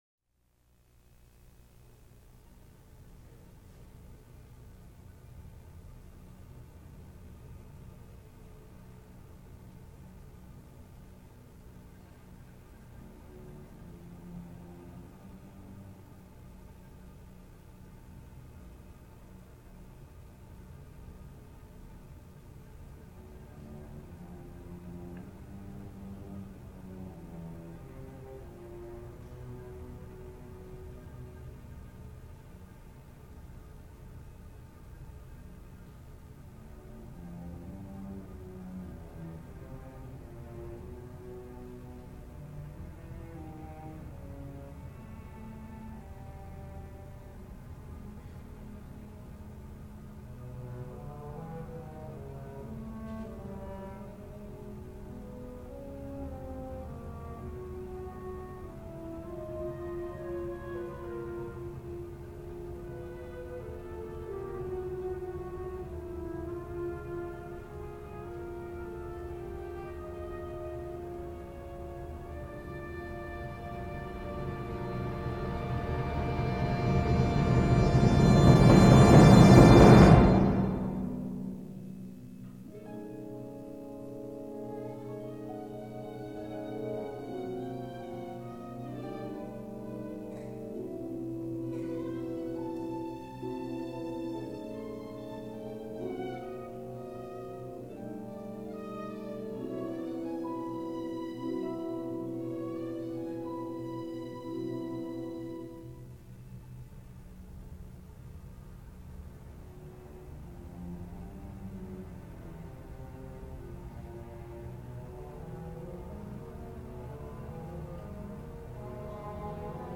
Ensemble: Full Orchestra